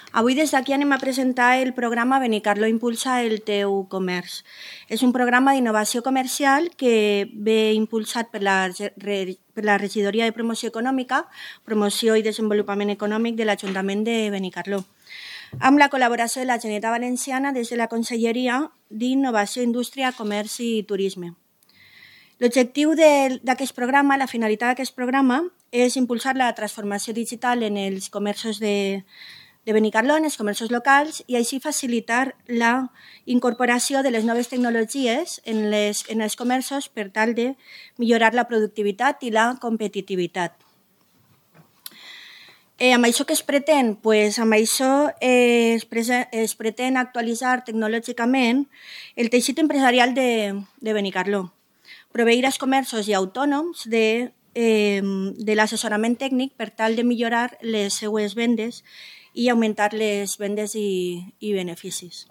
Escolta a Sonia Foix, regidora de comerç de Benicarló